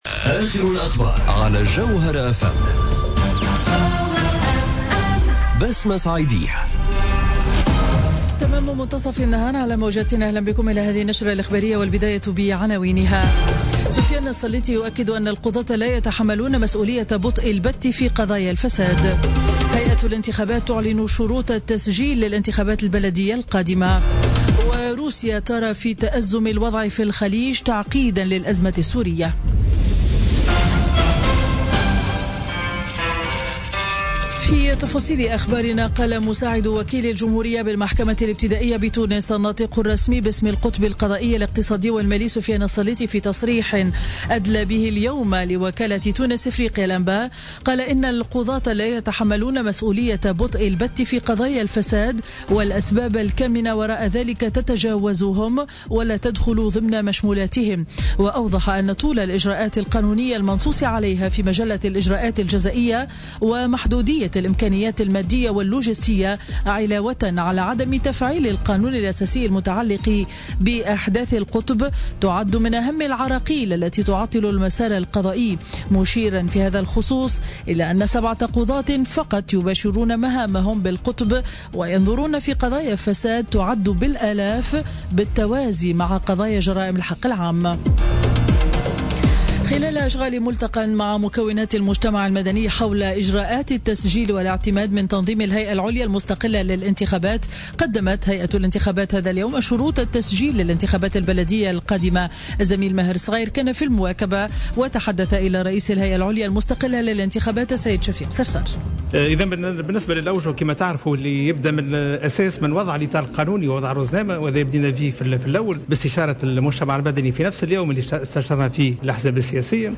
نشرة أخبار منتصف النهار ليوم الثلاثاء 13 جوان 2017